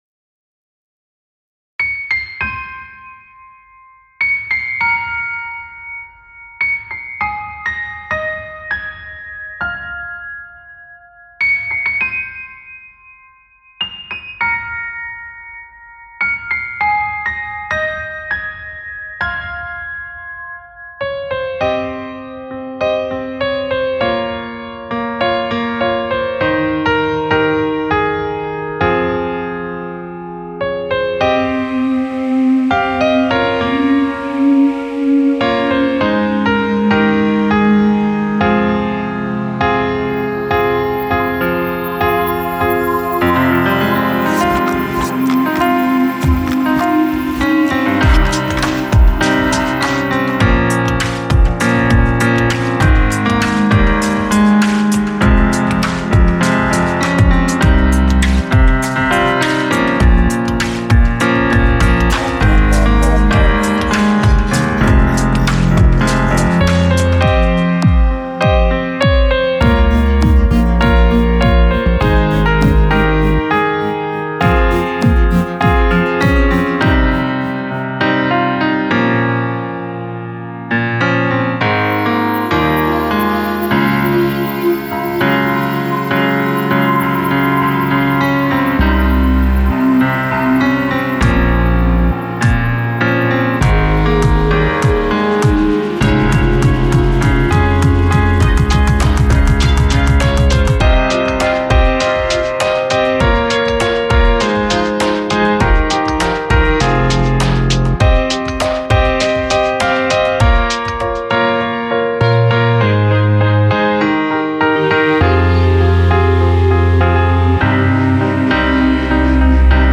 Mixé